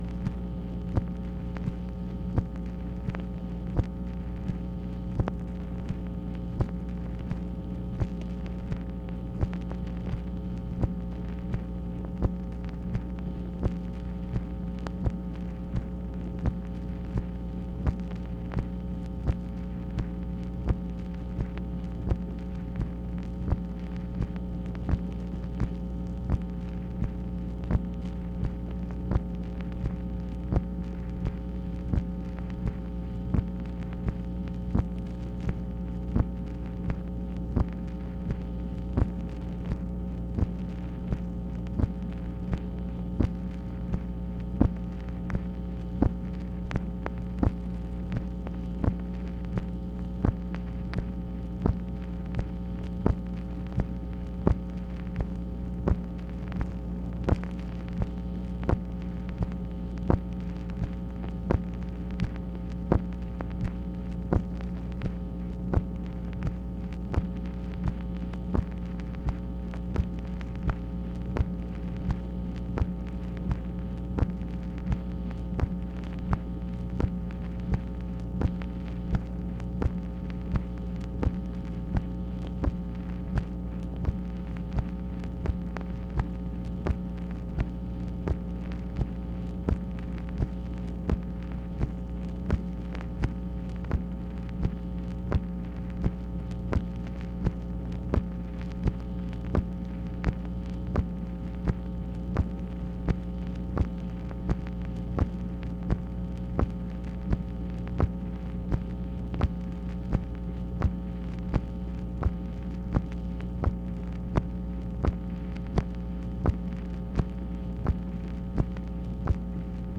MACHINE NOISE, February 16, 1967
Secret White House Tapes | Lyndon B. Johnson Presidency